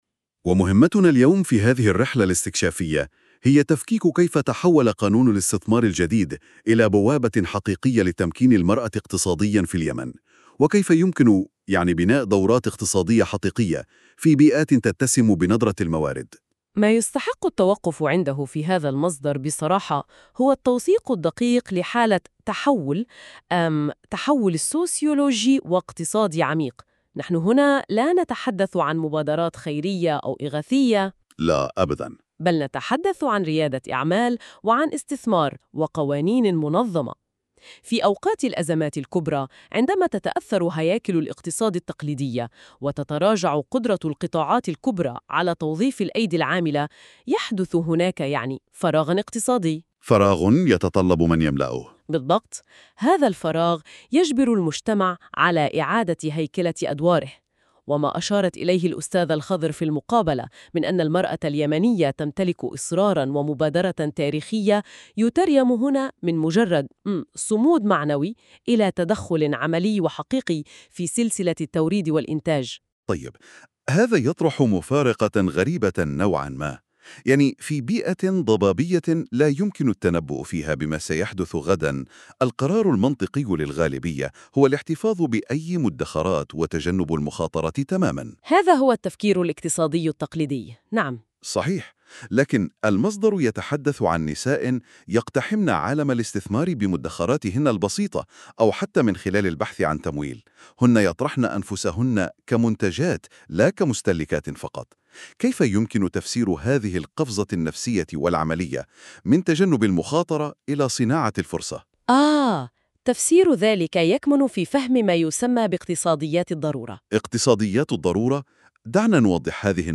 ملخص صوتي قصير للمادة المكتوبة مولد بالذكاء الاصطناعي بواسطة Notebook KLM